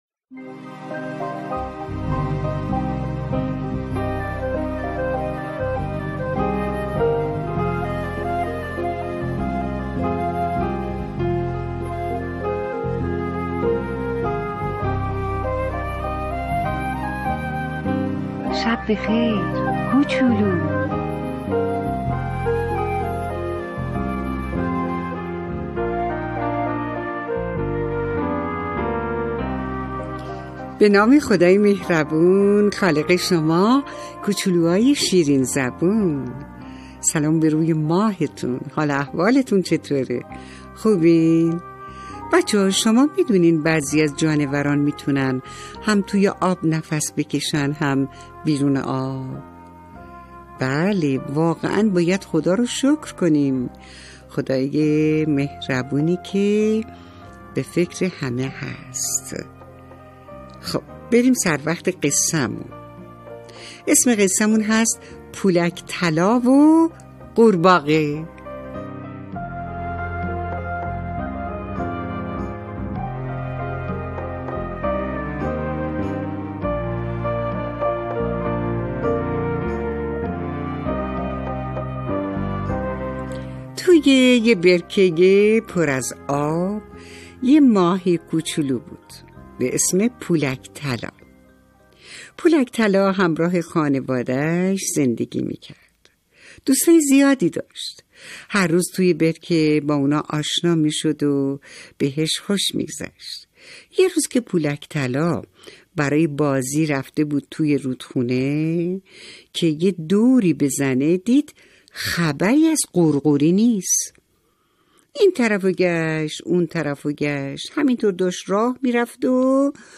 قصه صوتی کودکان دیدگاه شما 2,348 بازدید